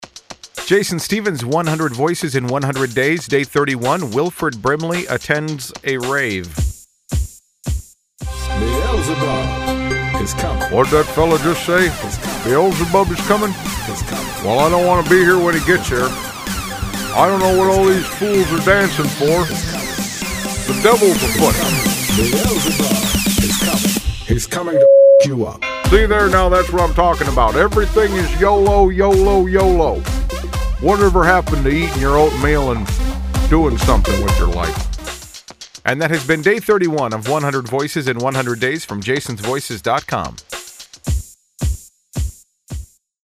Tags: celebrity sound alike, Wilford Brimley impression